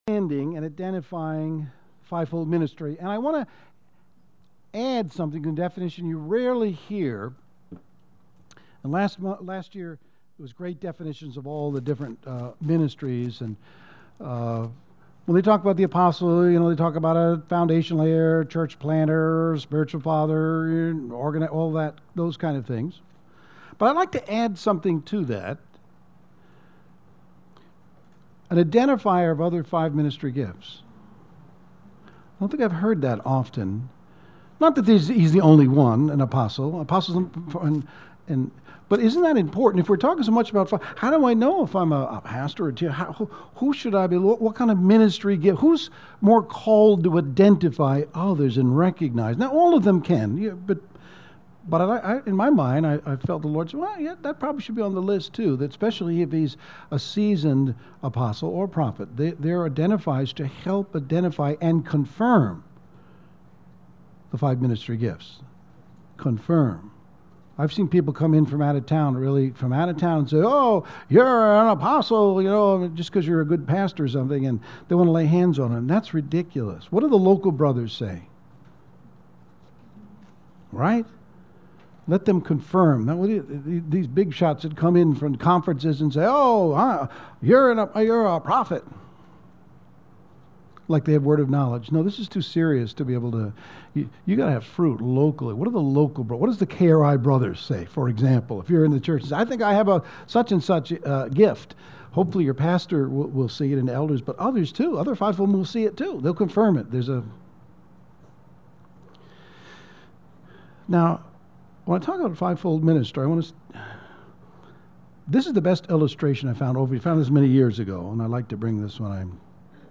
Audio Message